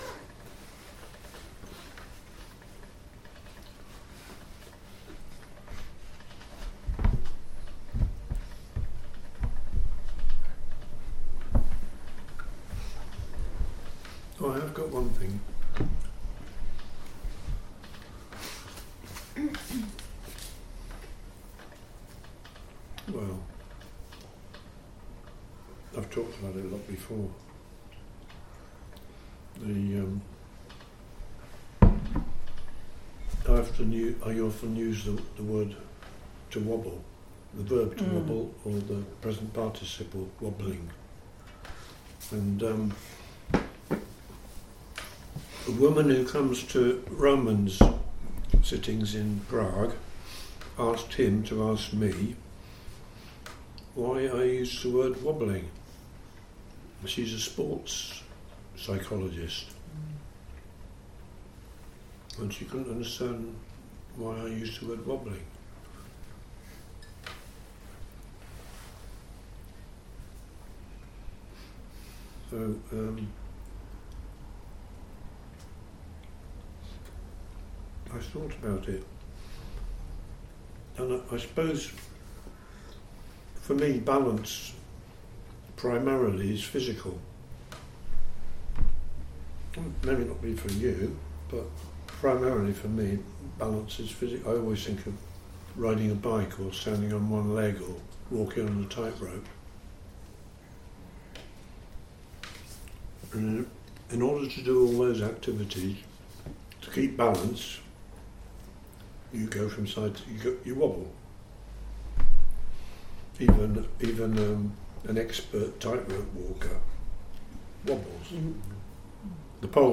“Wobbling” from Dogen Sangha Swindon retreat 2022